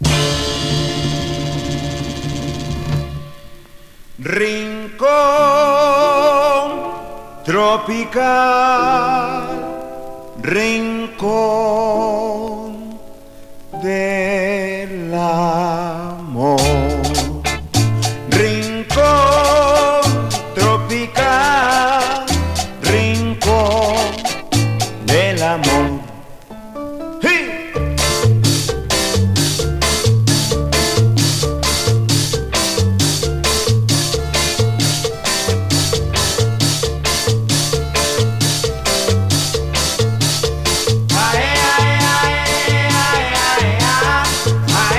Latin, Cumbia, Boogaloo　USA　12inchレコード　33rpm　Mono/Stereo